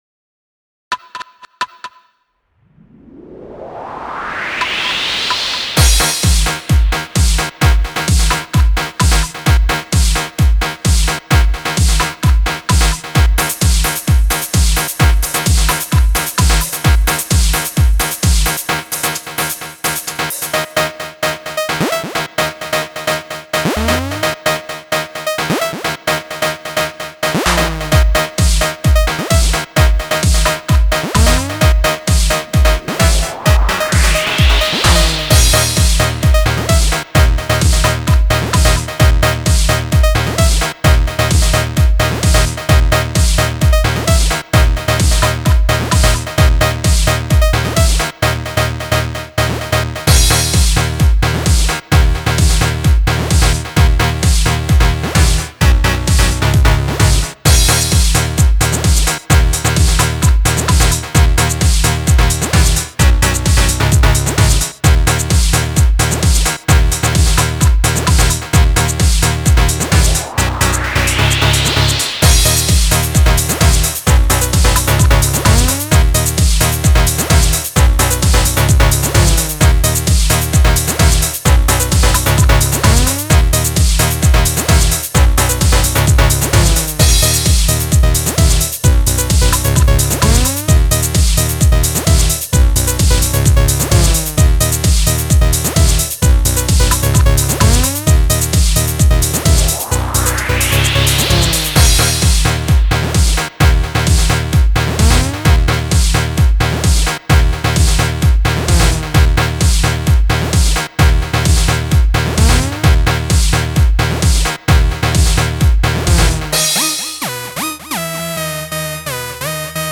Ремикс
КТО СОСКУЧИЛСЯ ПО ЕЖОВОМУ ЭЛЕКТРО